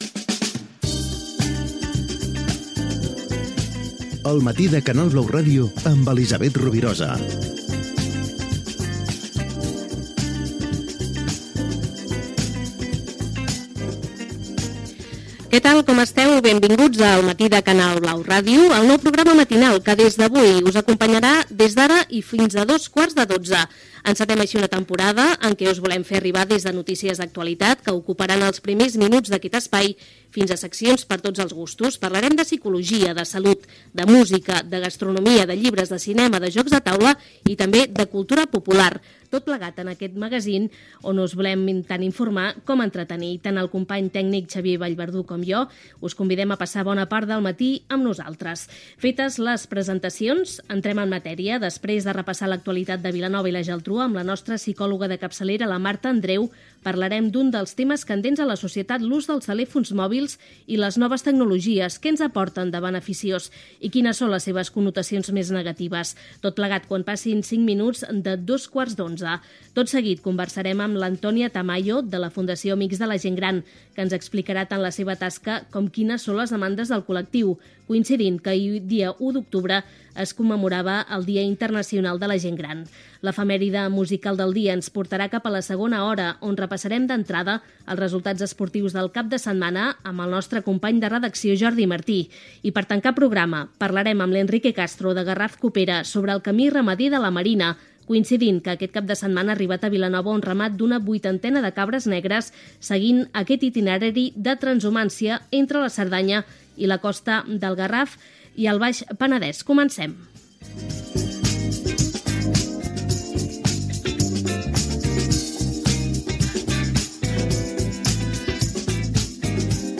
Primera edició del nou magazín.
Careta, presentació, temes, equip i sumari.